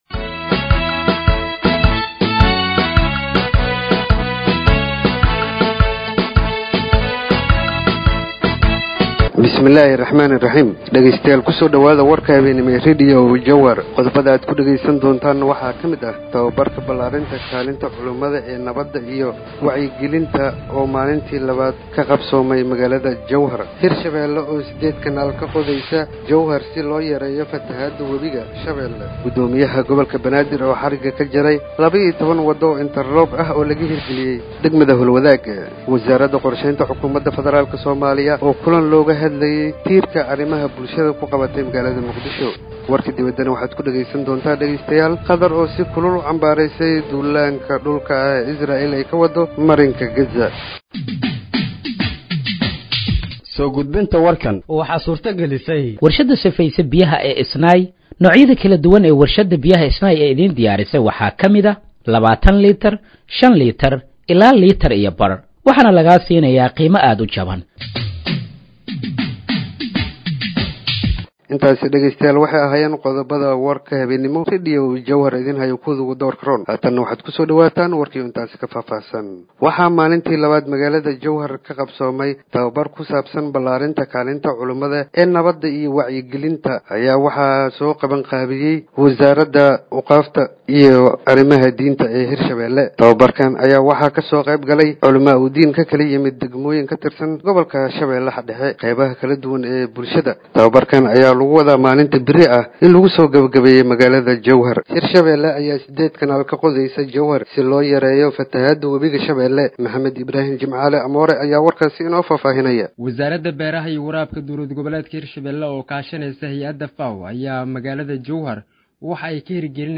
Dhageeyso Warka Habeenimo ee Radiojowhar 17/09/2025